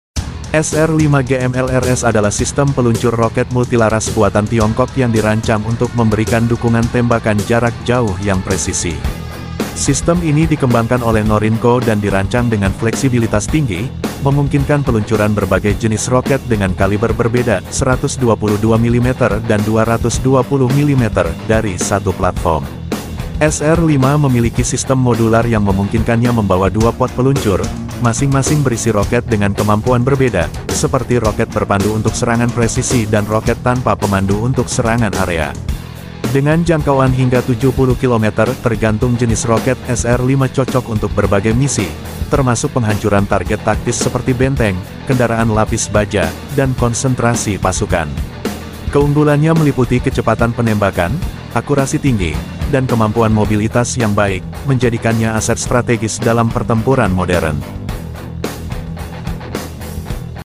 SR 5 GMLRS peluncur roket Tiongkok sound effects free download